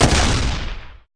Npc Robot Missilefire Sound Effect
npc-robot-missilefire.mp3